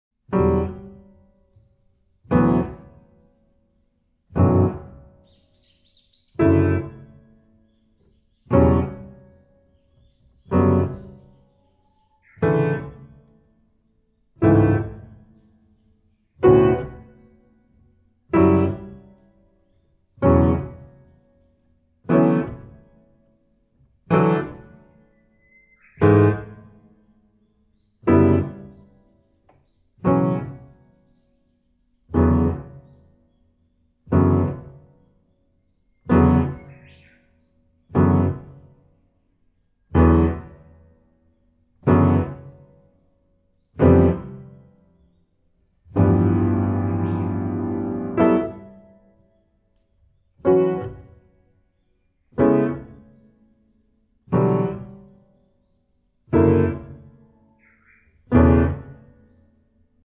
空間を意識させる旋律と響きの妙が強く印象に残る傑作です！